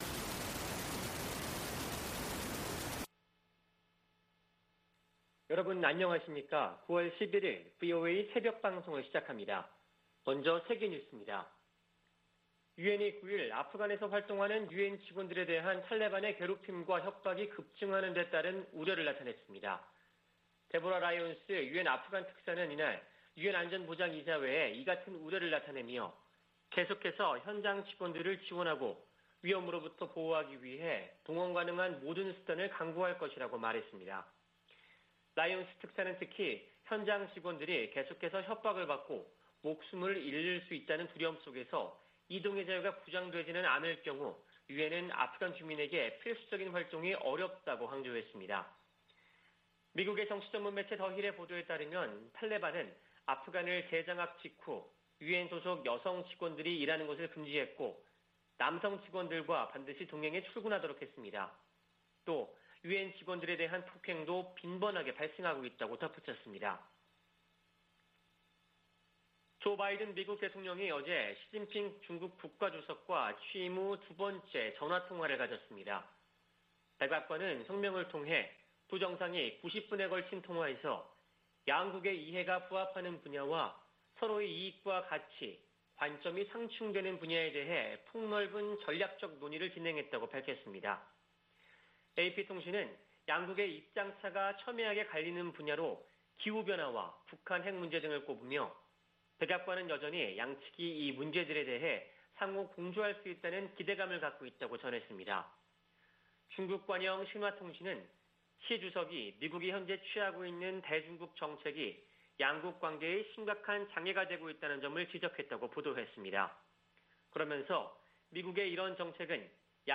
VOA 한국어 '출발 뉴스 쇼', 2021년 9월 11일 방송입니다. 미국 정부는 한반도의 완전한 비핵화가 여전히 목표이며, 이를 위해 북한과의 외교가 중요하다고 밝혔습니다. 북한의 정권수립 73주년 열병식은 군사 보다는 경제 분야에 집중됐다고 미국의 전문가들이 분석했습니다. 중국과 북한의 강한 신종 코로나바이러스 대응 조치 때문에 탈북 네트워크가 거의 와해 수준인 것으로 알려졌습니다.